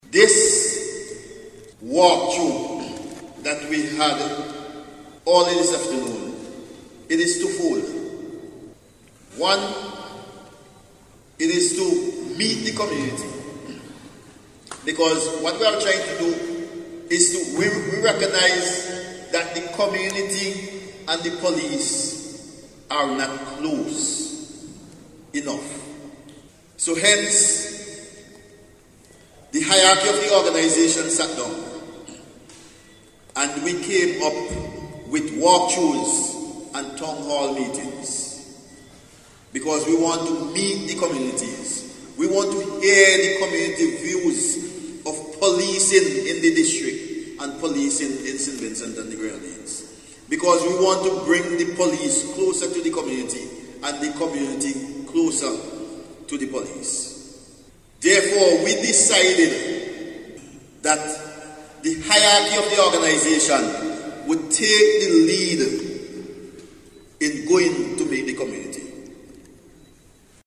The Royal St. Vincent and the Grenadines Police Force has restated their commitment to implement measures to combat crime and violence in St. Vincent and the Grenadines during a recent Town Hall meeting.
And, Deputy Commissioner of Police, Frankie Joseph says the Police are seeking to foster a better relationship with the public through the hosting of these activities.